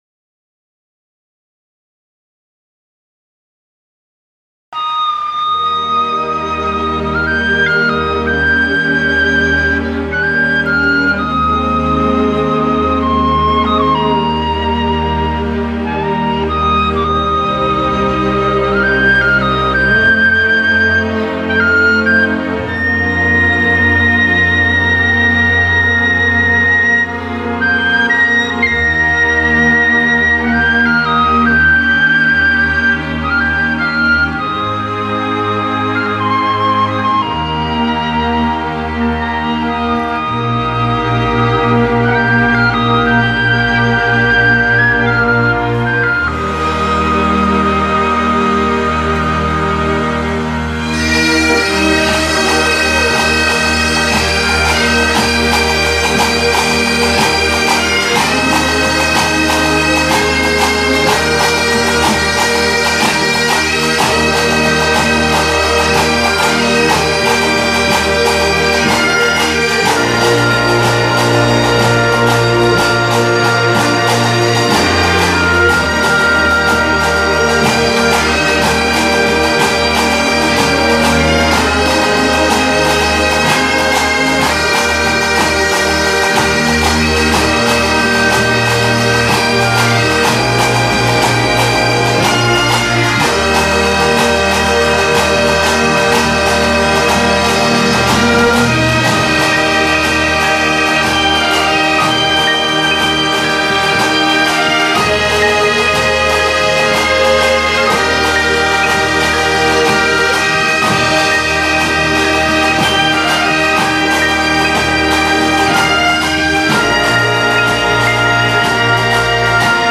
Amazing grace - Scottish bagpipes and symphony orchestra.mp3